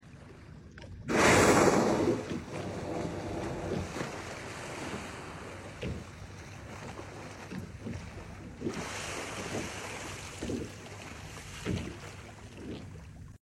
Sound of a heathly young humpback whale.